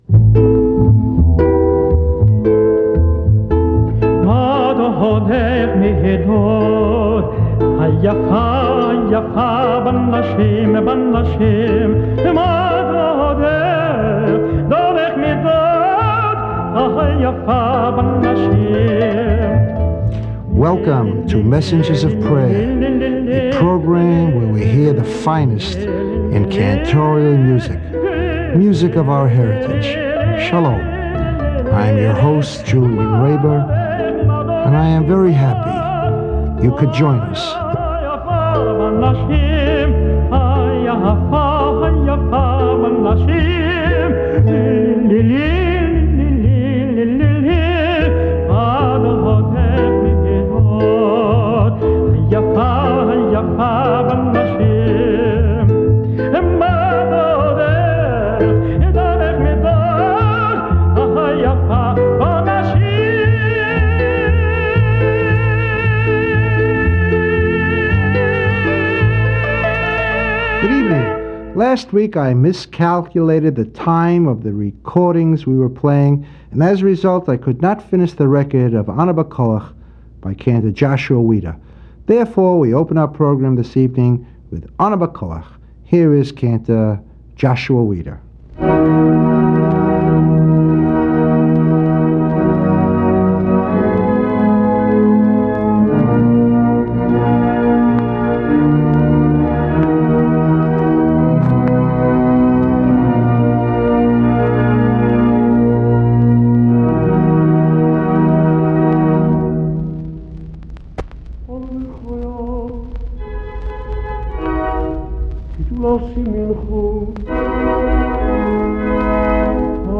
PRAYER Ana B’Koach